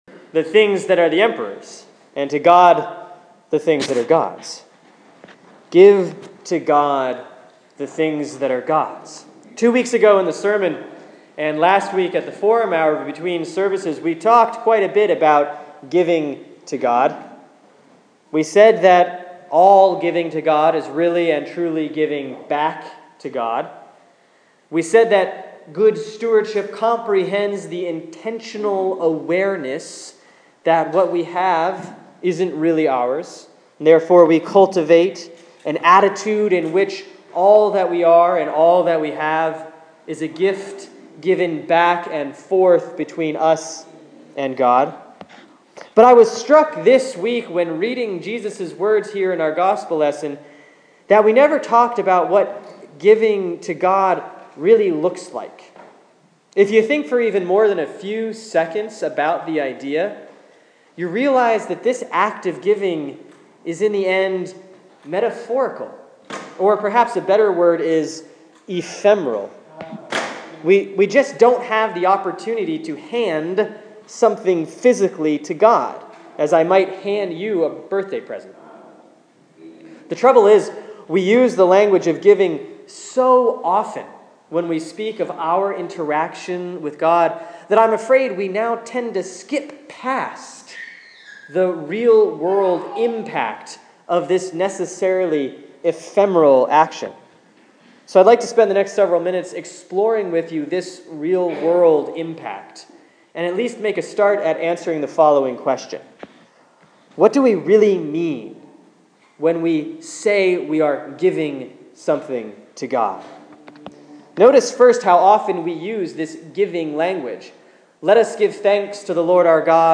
Sermon for Sunday, October 19, 2014 || Proper 24A || Matthew 22:15-22